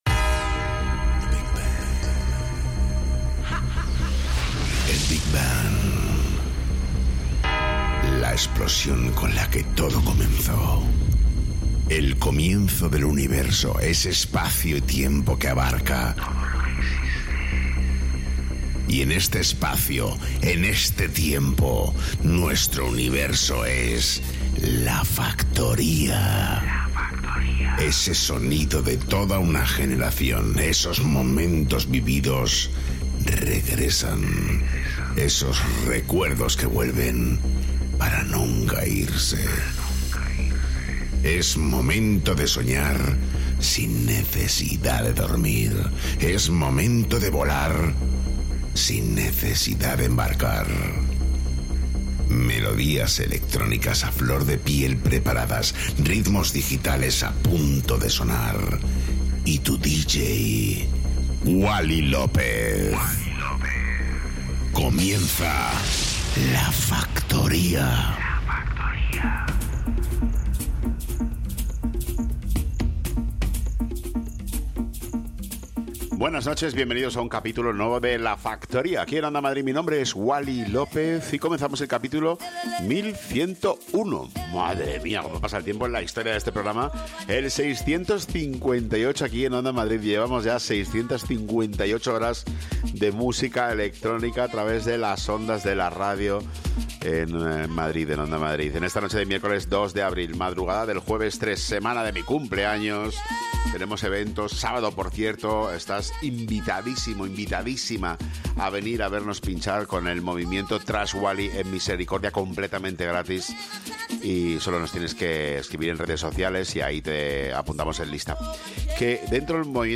Wally López, el DJ más internacional de Madrid retoma La Factoría para todos los madrileños a través de Onda Madrid.